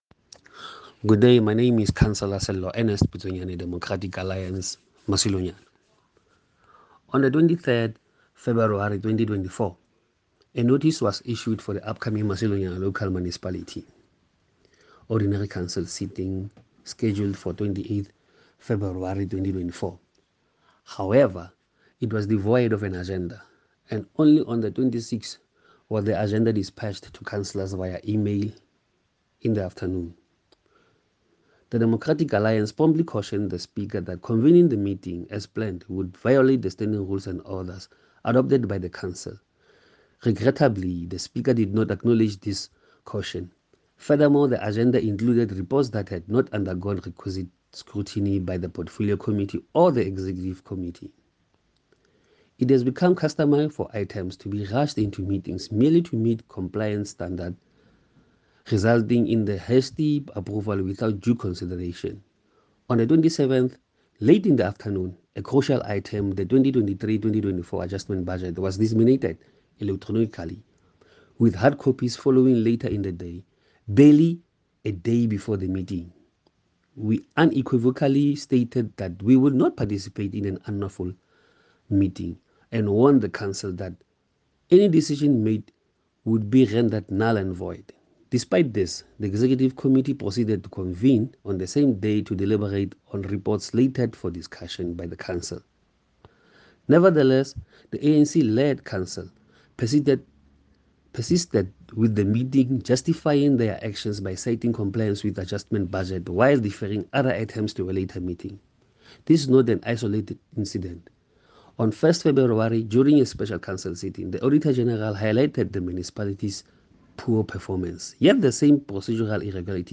English soundbite by Cllr Ernest Putsoenyane and